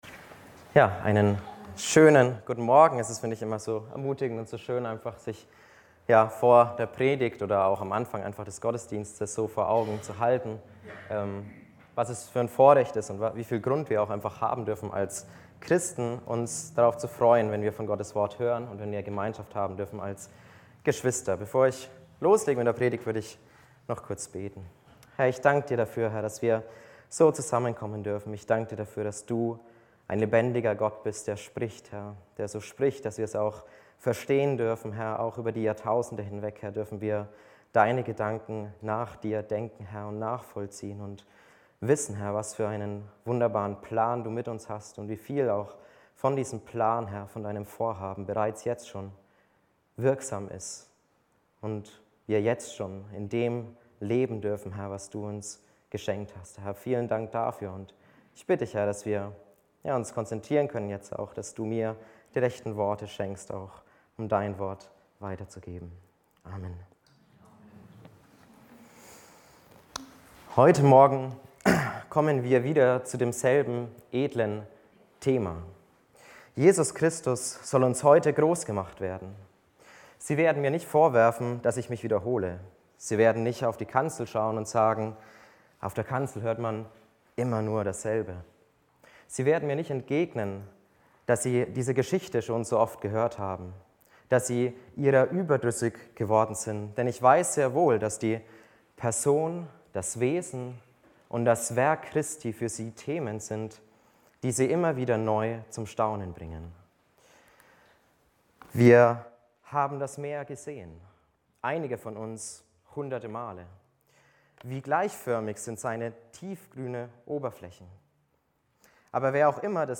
Die Gliederung der Predigt ist: Die alte Herrschaft (Verse 12 +13a) Gottes Herrschaft (Verse 13b + 14) HausKreisLeitfaden Aufnahme (MP3) 46 MB PDF 2 MB Zurück Völlige Identifikation mit Jesus Weiter Befreit zum Leben